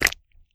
STEPS Pudle, Walk 28.wav